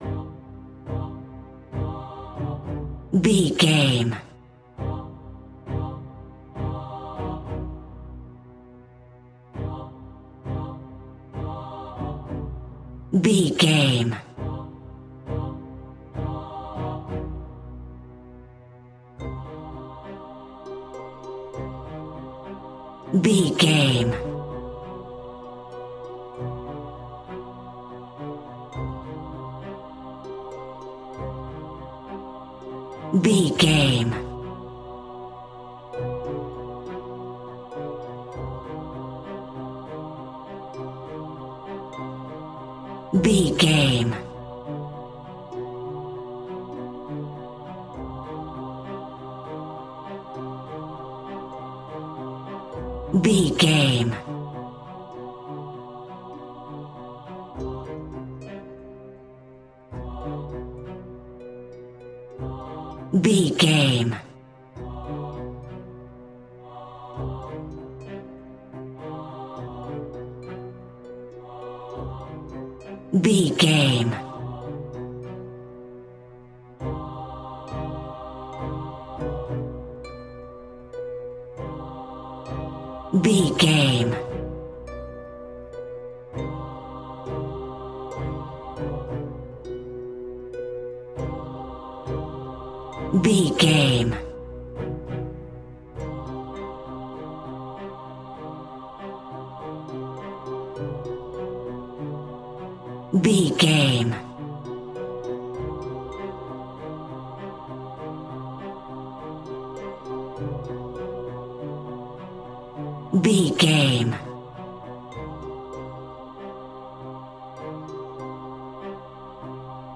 Haunted Church Choir.
Aeolian/Minor
ominous
eerie
harp
strings
vocals
horror music